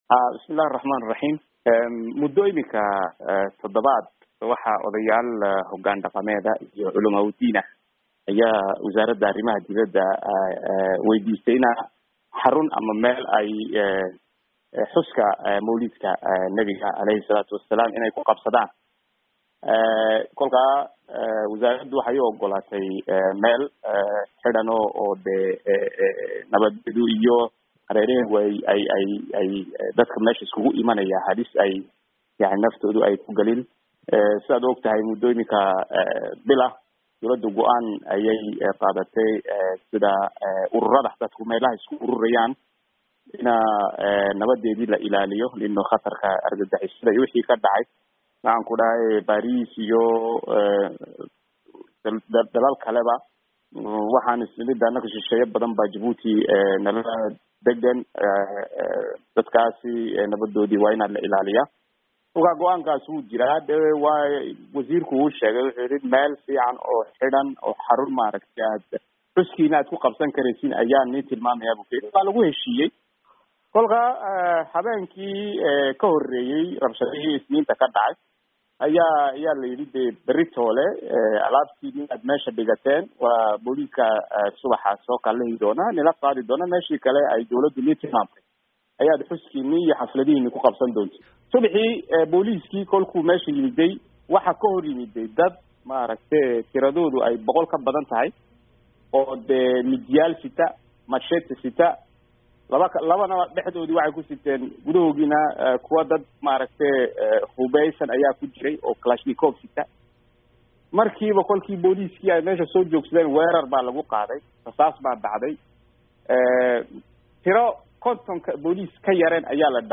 Wareysi: Wasiirka Arrimaha Dibedda Jabuuti